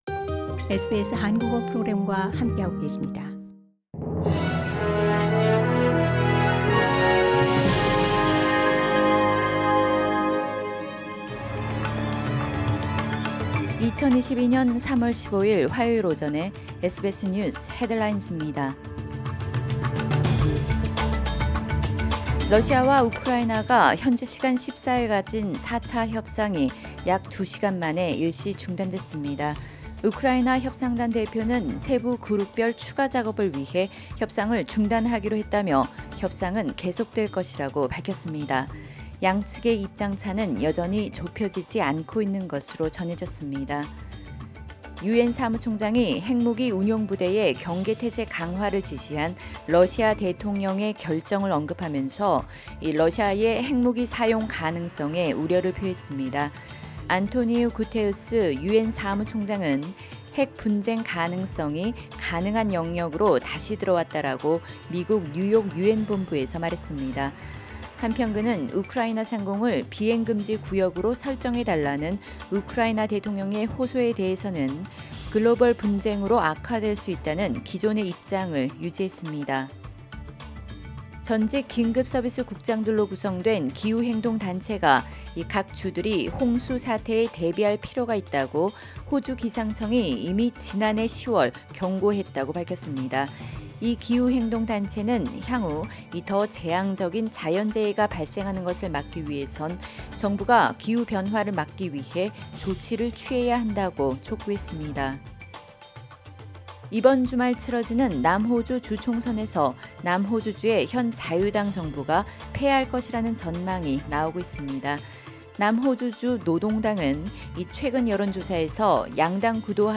SBS News Headlines…2022년 3월 15일 화요일 오전 뉴스
2022년 3월 15일 화요일 오전 SBS 뉴스 헤드라인즈입니다.